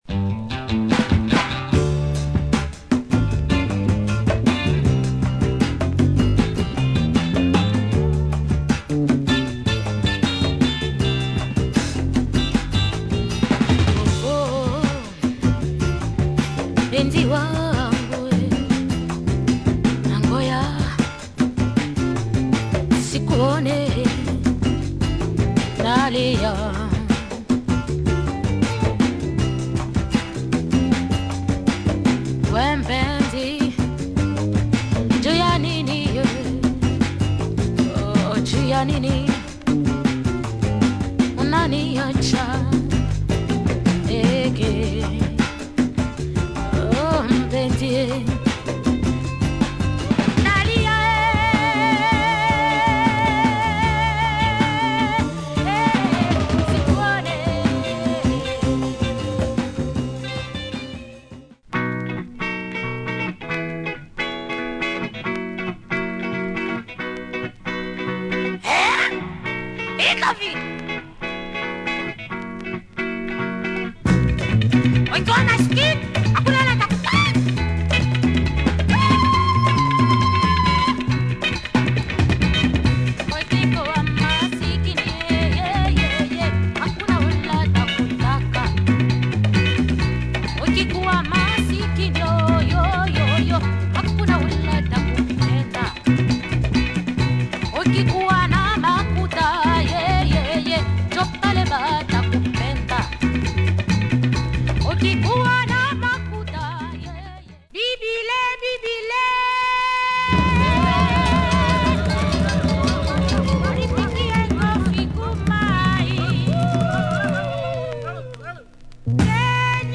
Surface marks but plays very well, it is a loud pressing.